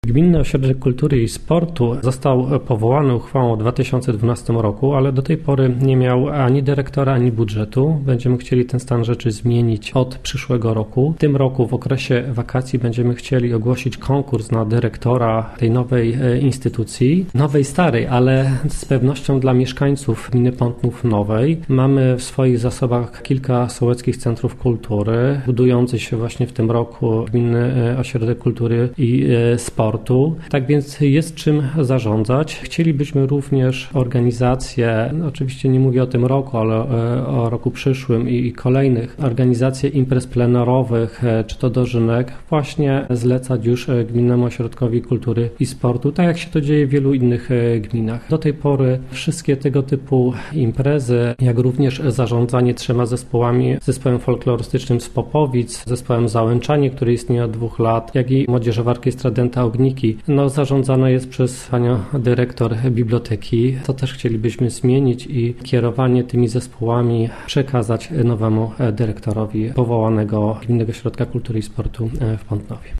– mówił wójt, Jacek Olczyk.